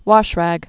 (wŏshrăg, wôsh-)